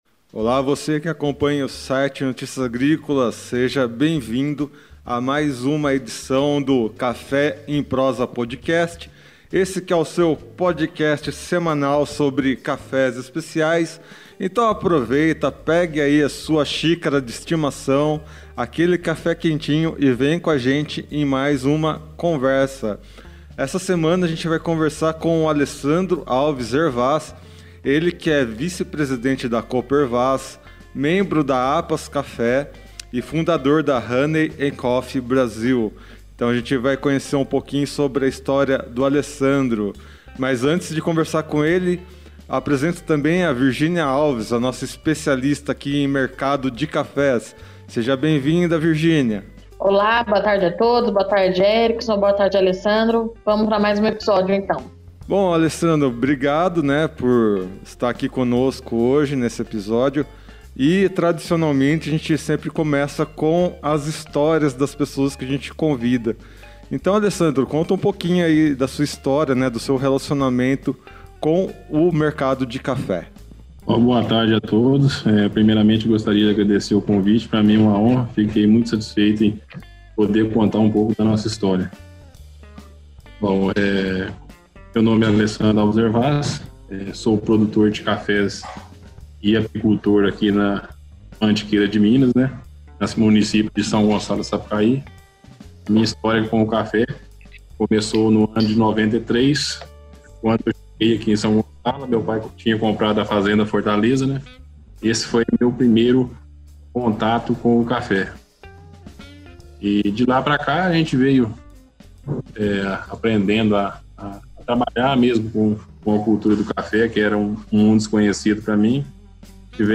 Em conversas descontraídas, essas pessoas contarão suas histórias e trarão suas ligações com essa bebida que é uma das mais apreciadas no mundo todo.